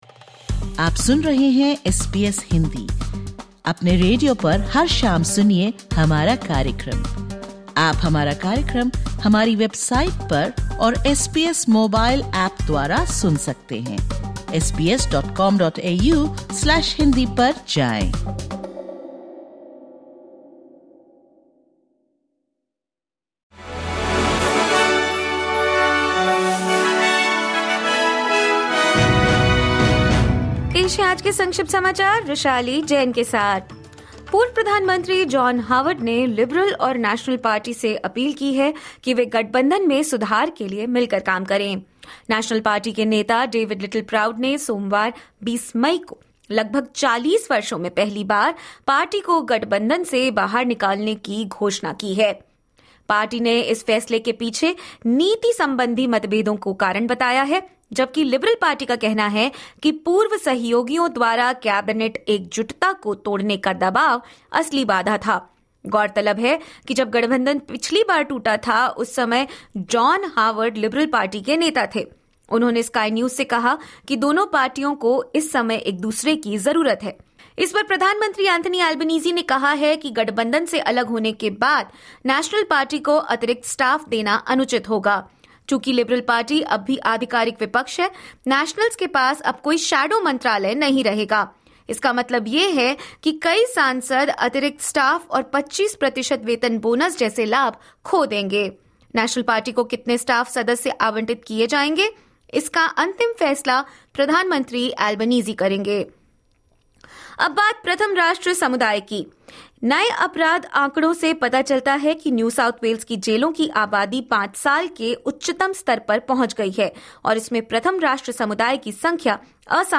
Listen to the top News of 21/05/2025 from Australia in Hindi.